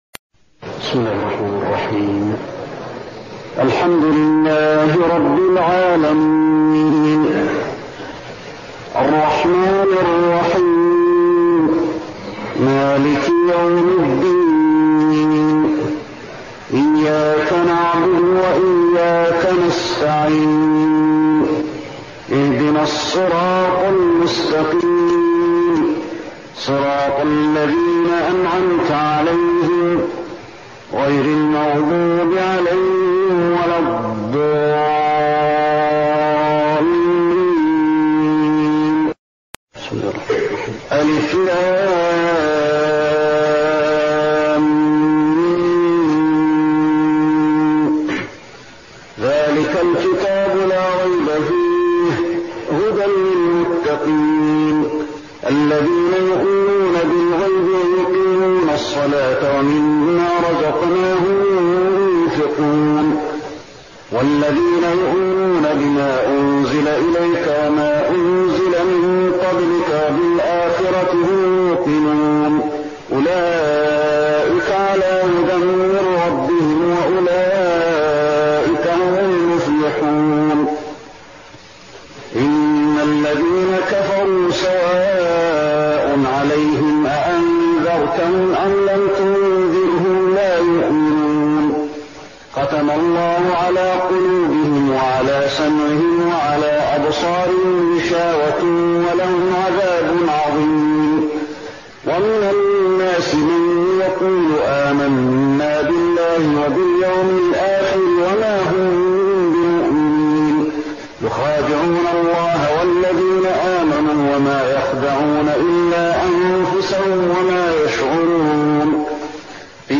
تهجد رمضان 1415هـ من سورة البقرة (1-77) Tahajjud night Ramadan 1415H from Surah Al-Baqara > تراويح الحرم النبوي عام 1415 🕌 > التراويح - تلاوات الحرمين